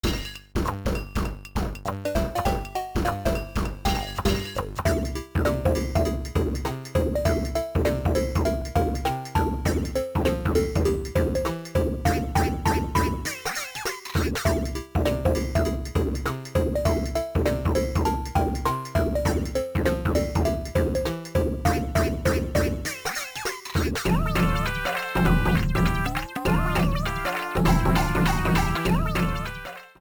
Self-recorded from emulator
Fair use music sample